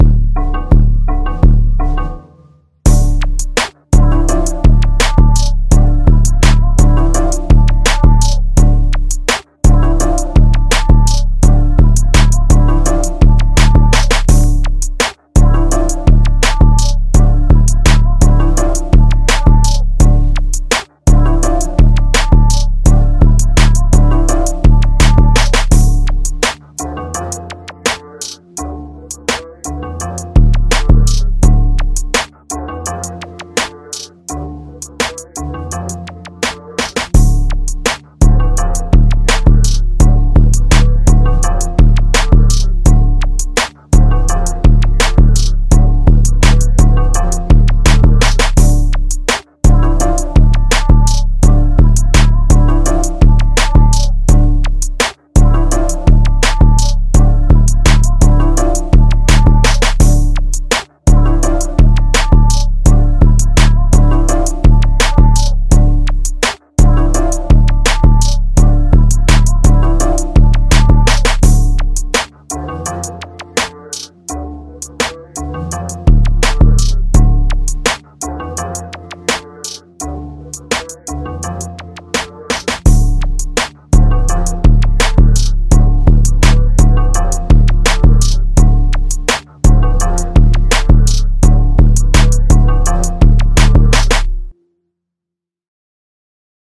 Hip Hop
A Minor